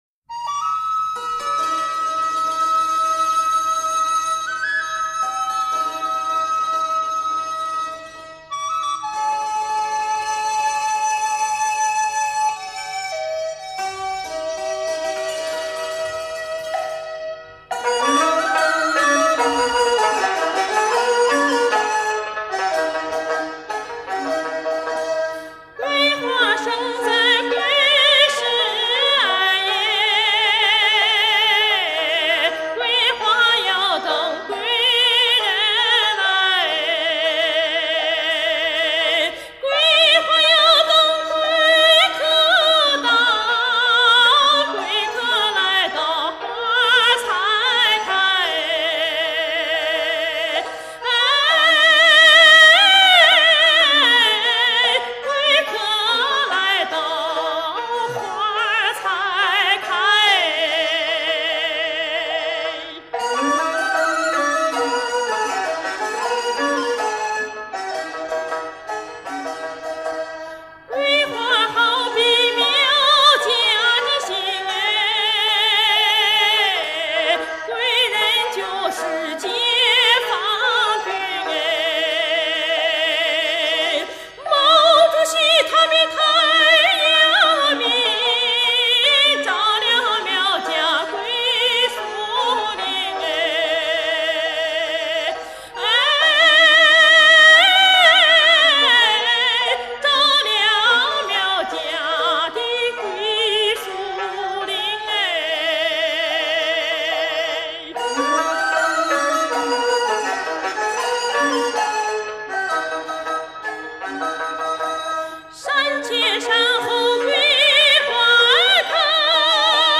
著名女高音歌唱家